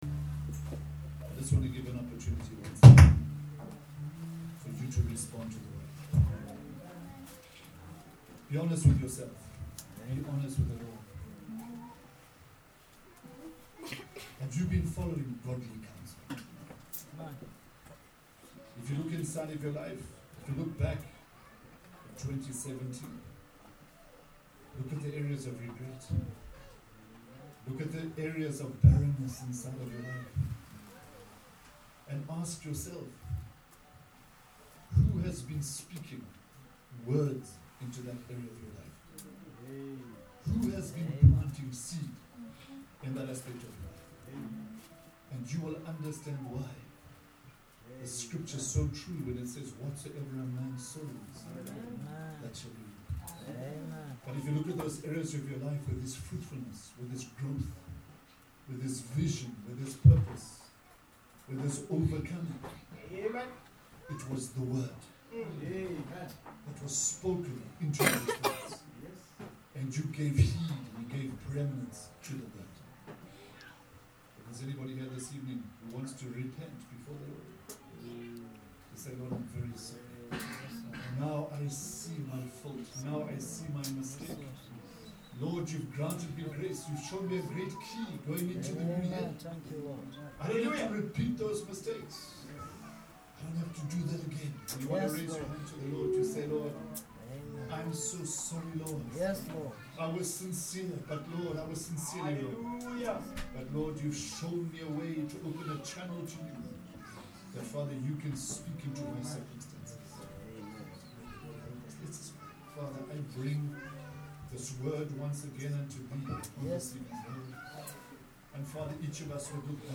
WHY RESOLUTIONS FAIL Church Services